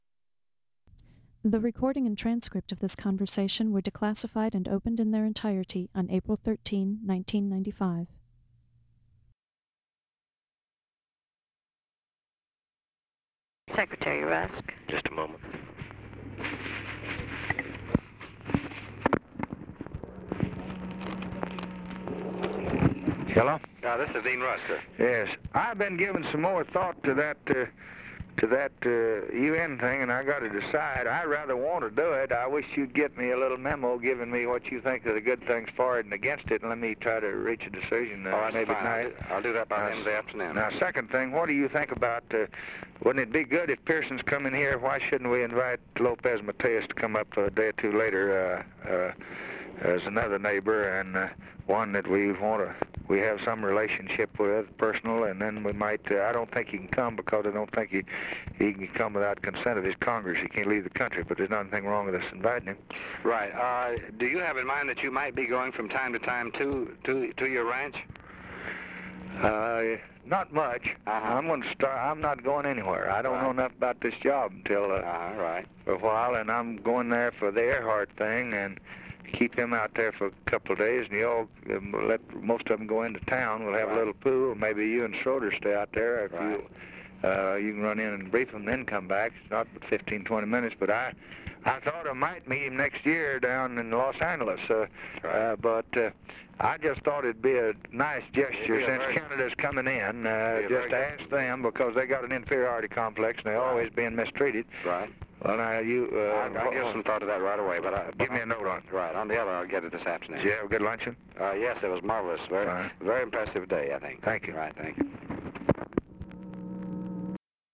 Conversation with DEAN RUSK, December 6, 1963
Secret White House Tapes